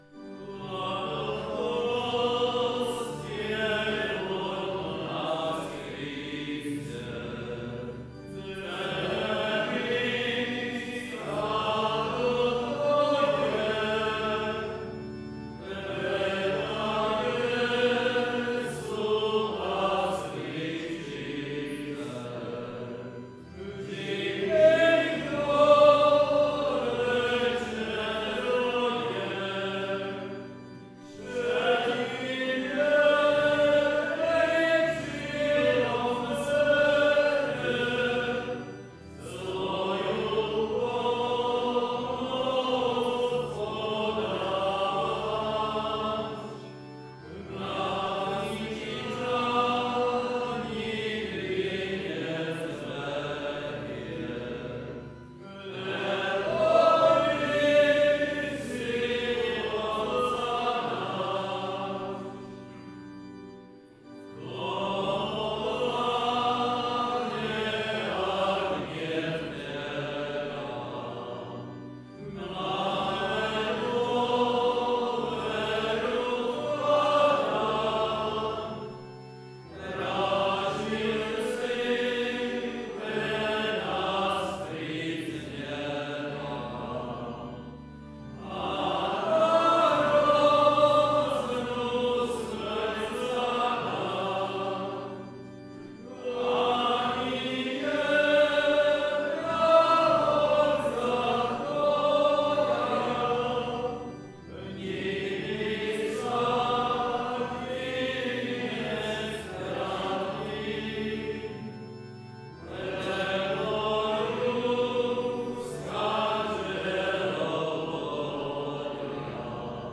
Presbyterská konferencia vo Svinici